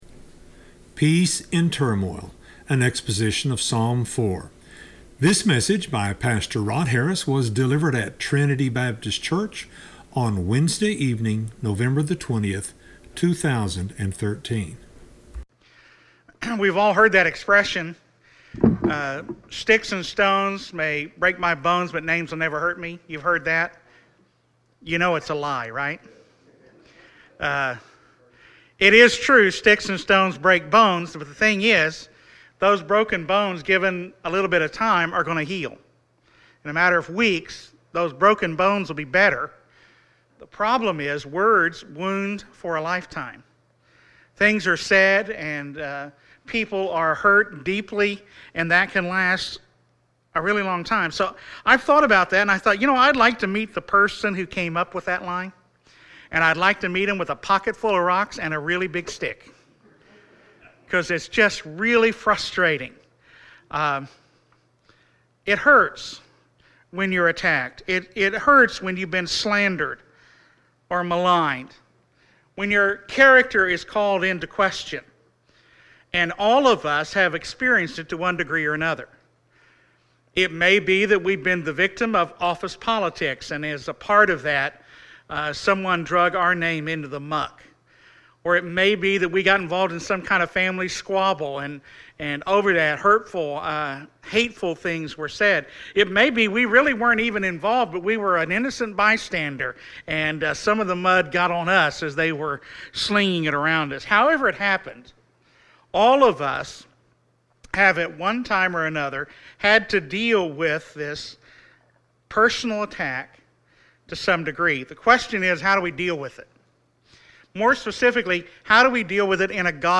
Wednesday evening Sermons - TBCTulsa - Page 5